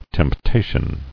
[temp·ta·tion]